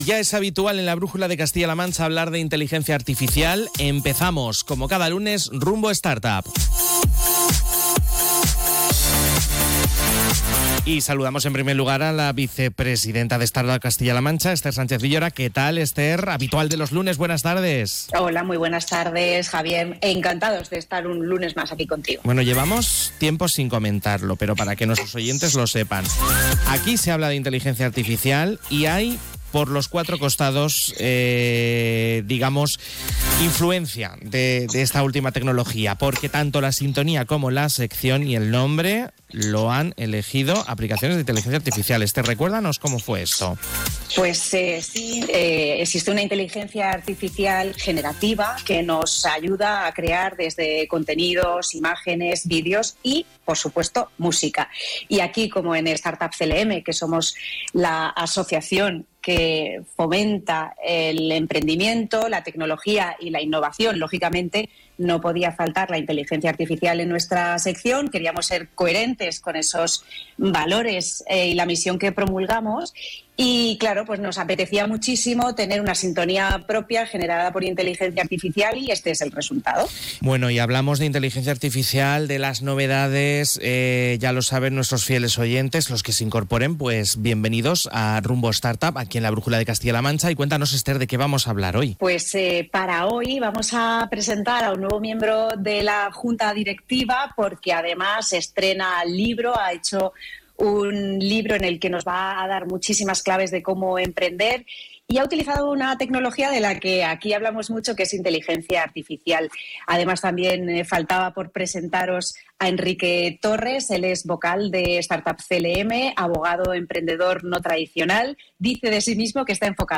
Imagínate viajar escuchando una melodía que ha sido creada por un algoritmo generativo, 🤖 extendiendo sus notas alrededor del estudio de Onda Cero.